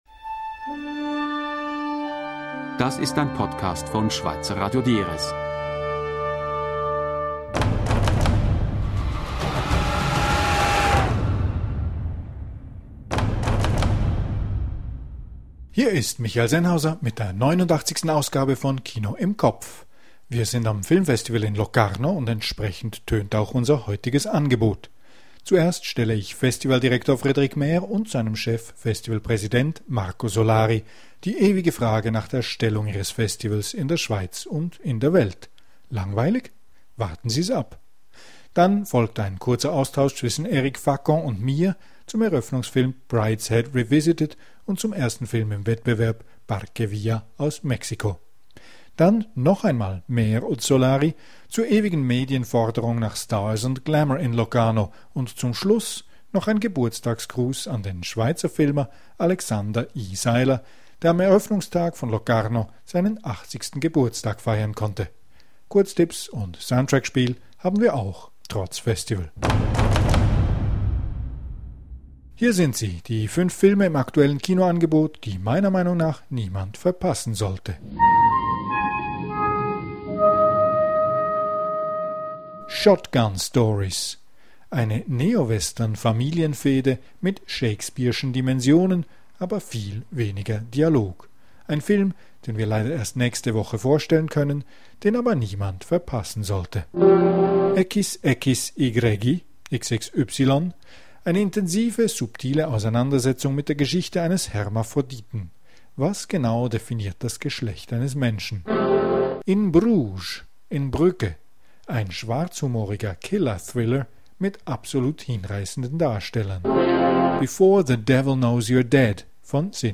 Wir sind am Filmfestival Locarno, und entsprechend tönt auch unser heutiges Angebot.